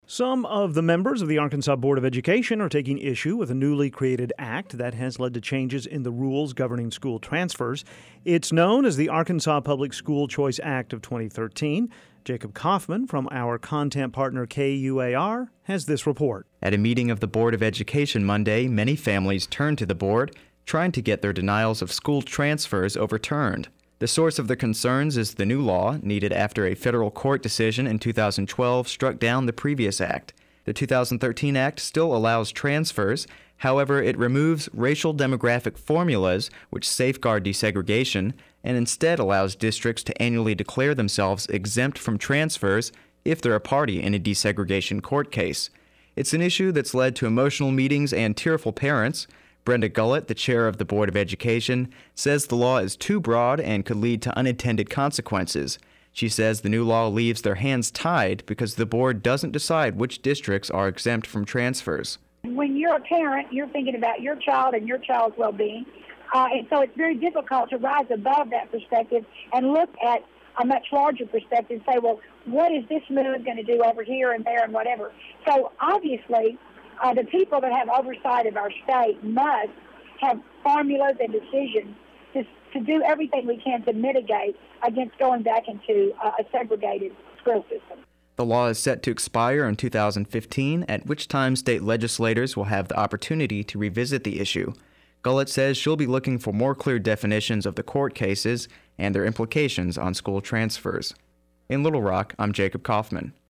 has this report: